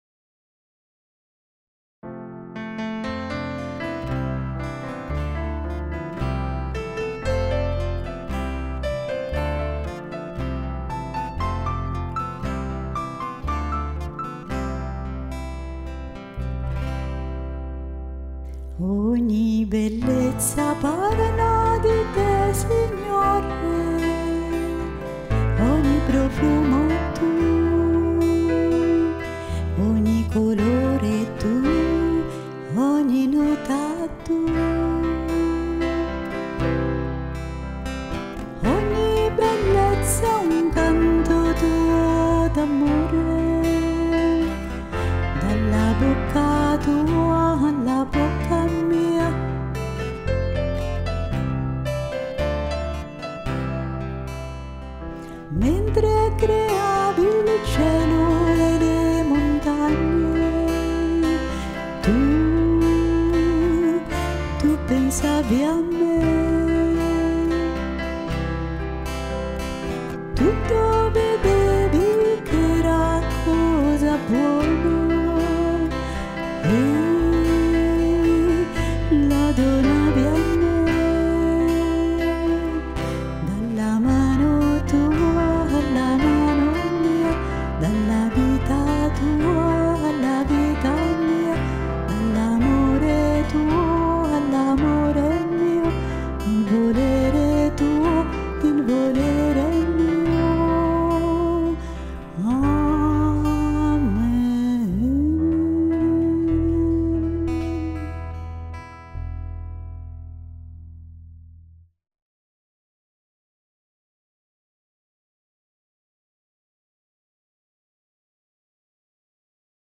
Bellezza canto mp3